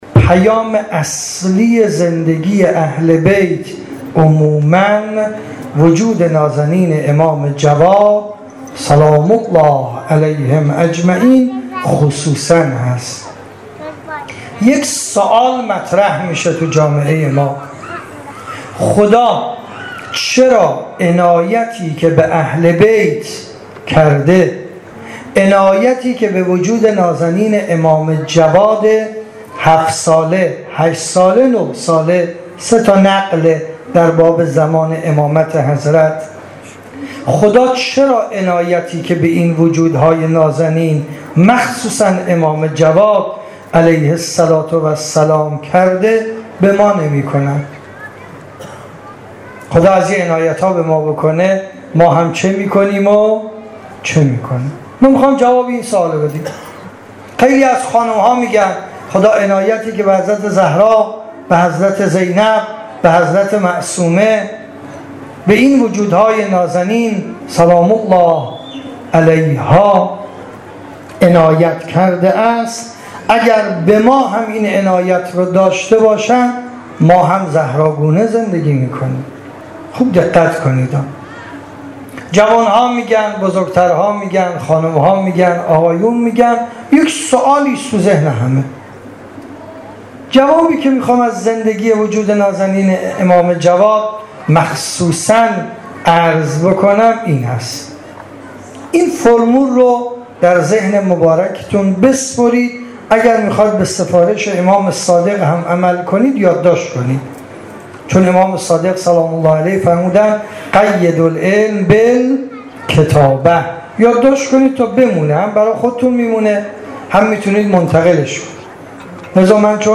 در ویژه برنامه وادی عشق که به مناسبت شهادت امام جواد (ع) در امامزاده سلطان امیراحمد بن موسی المبرقع برگزار شد